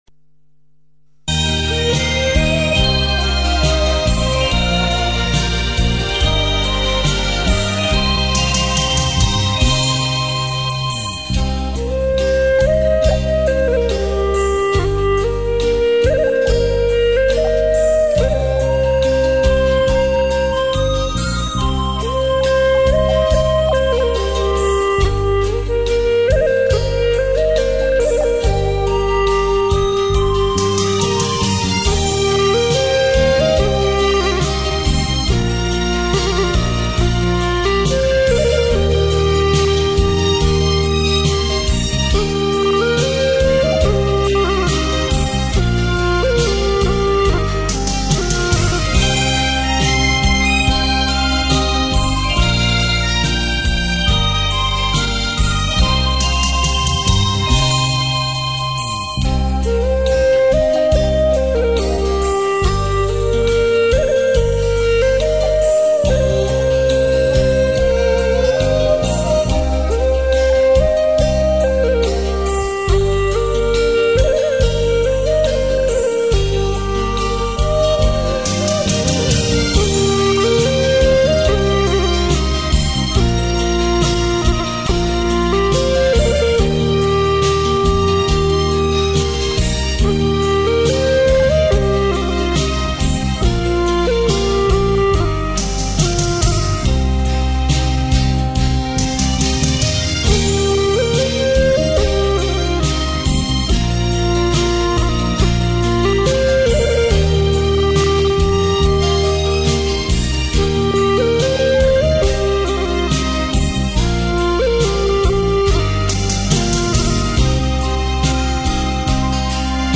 调式 : 降E